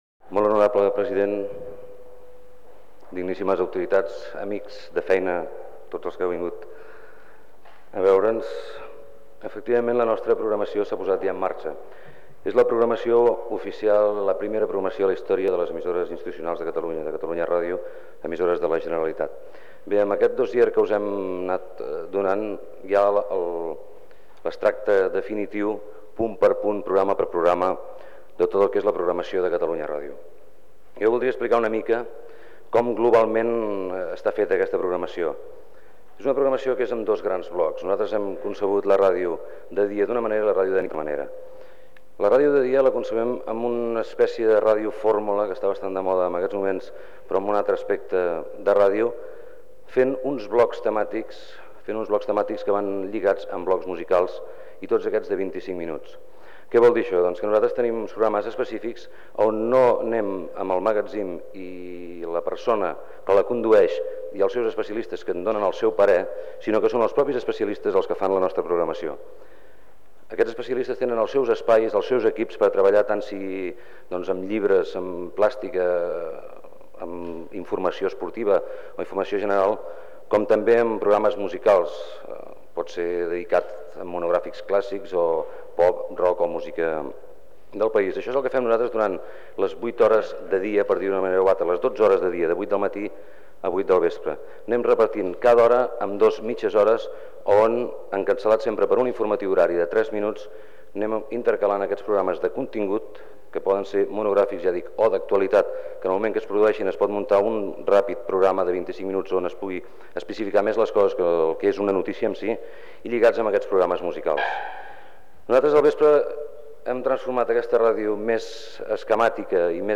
Paraules del president de la Generalitat de Catalunya Jordi Pujol.
Informatiu